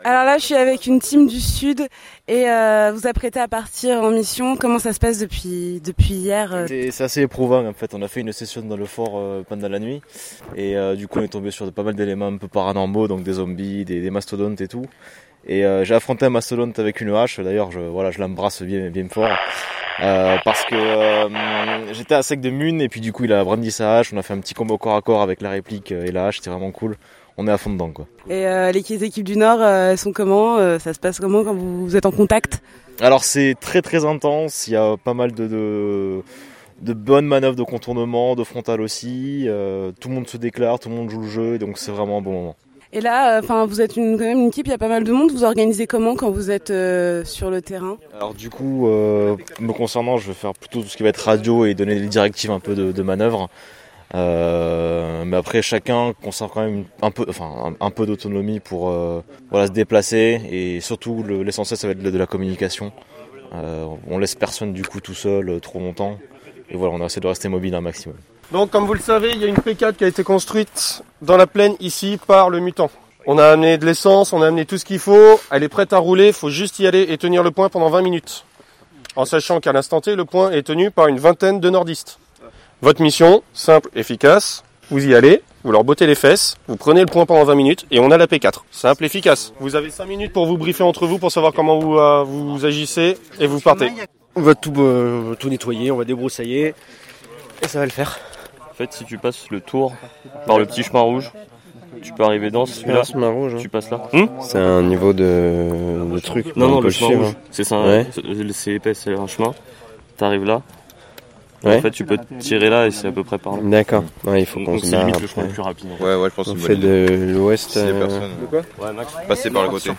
En plein milieu d’une zone irradiée notre reporter a tenté d’interviewer un zombie, sans grands résultats.